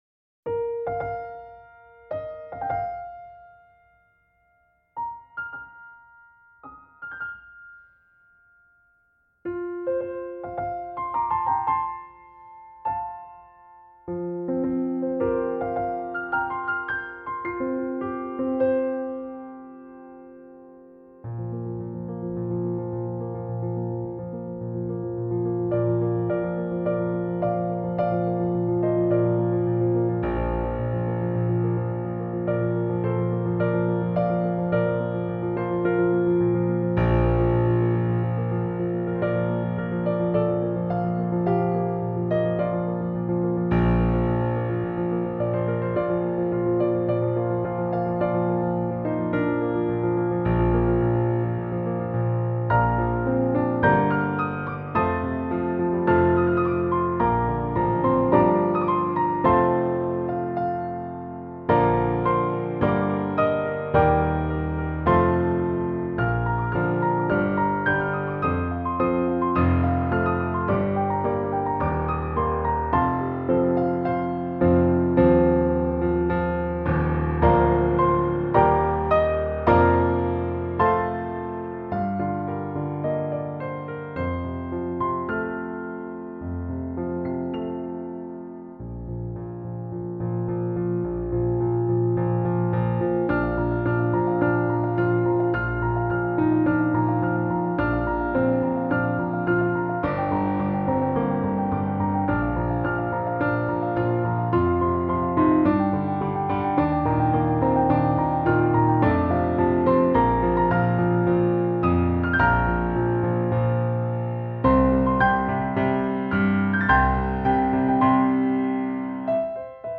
instrumentation: Piano Duet